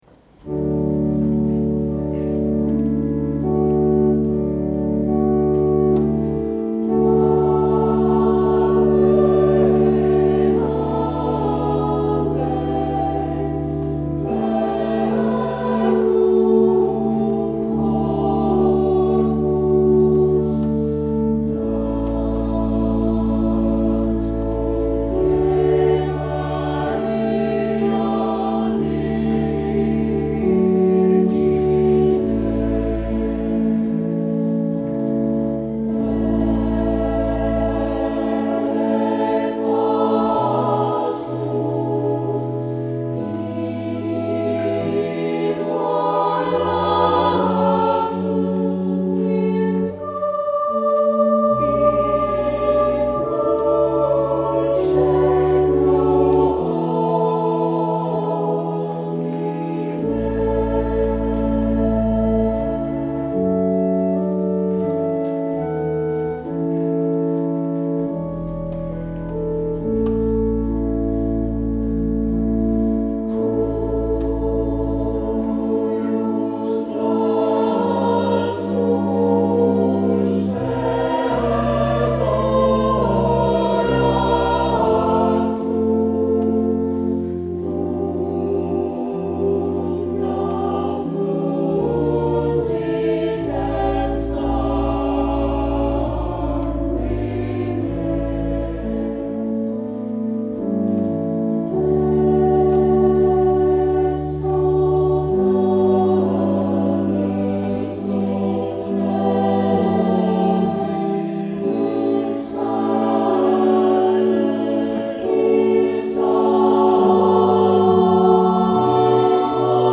Saint Clement Choir Sang this Song
Anthem